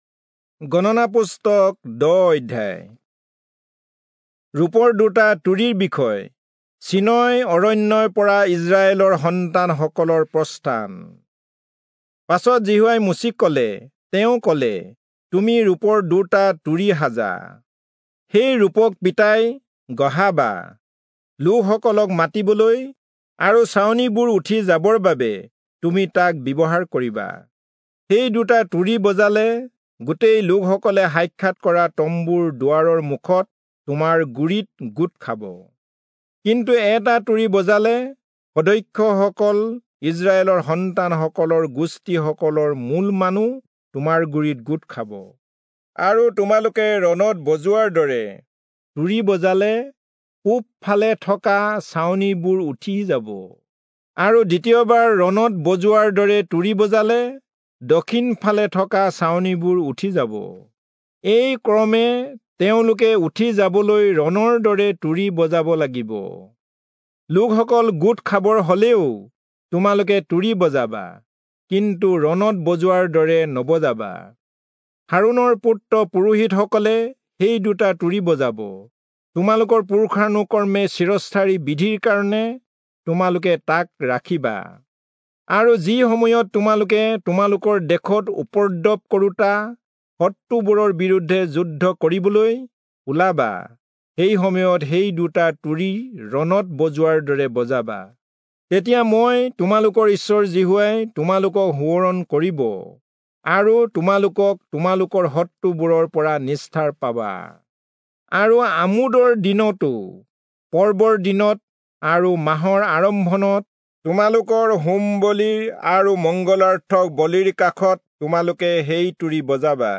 Assamese Audio Bible - Numbers 5 in Orv bible version